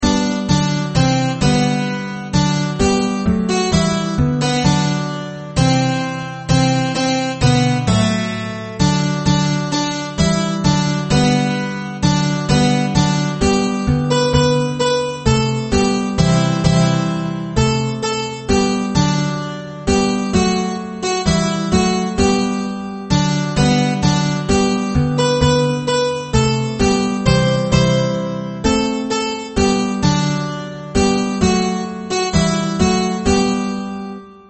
Нетрудно заметить, что это та же самая мелодия, которую в 1991 году воспроизводил на Би-би-си Сева Новгородцев.